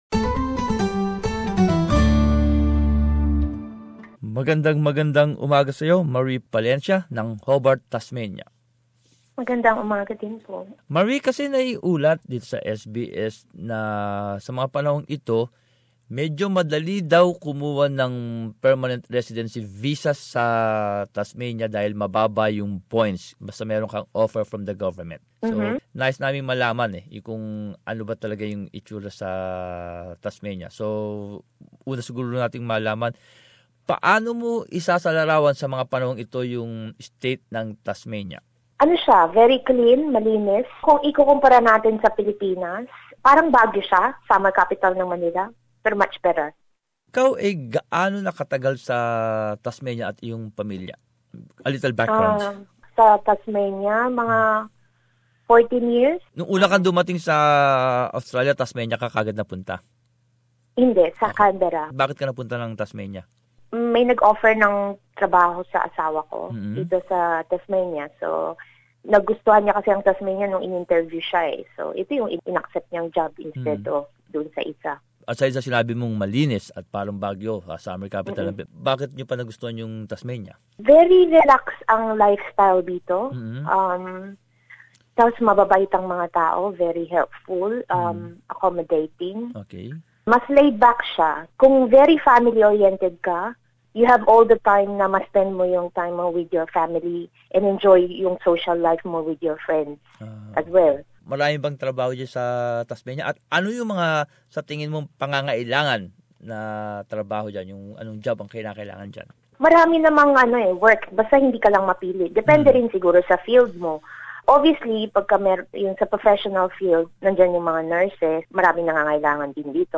Image: a community of migrants operates a communal garden (AAP) We have interviewed a Filipino-Australian who has been living there for 14 years.